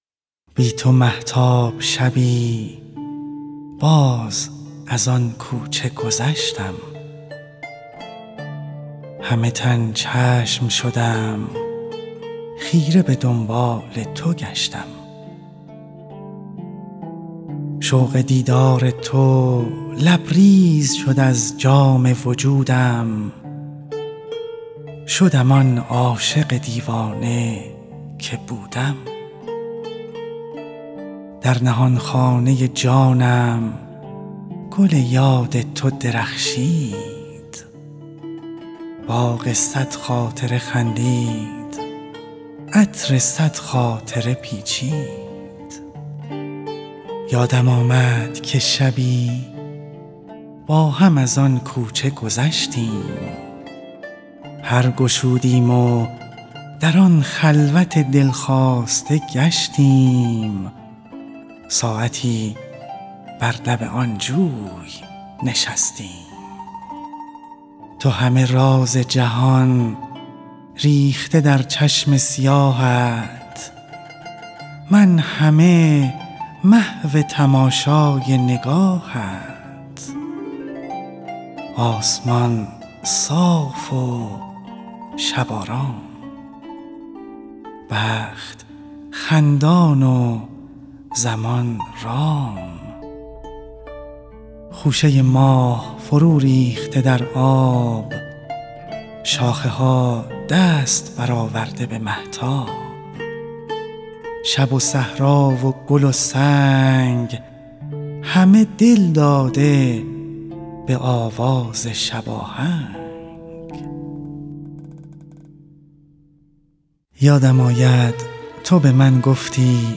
«کوچه» یکی از معروف‌ترین سروده‌های فریدون مشیری است که به صورت موسیقی گفتار اجرا شده است.
به گزارش خبرنگار فرهنگی خبرگزاری تسنیم، «پرواز خیال» مجموعه آثارِ صوتی است که با هدفِ مروری بر سروده‌های شعرای معاصر ایران و جهان تهیه و ضبط شده است. در این مجموعه سروده‌هایی مهم در ادبیات ایران و جهان انتخاب شده و با همراهی موسیقی خوانده شده‌اند.
موسیقی گفتار(دکلمه)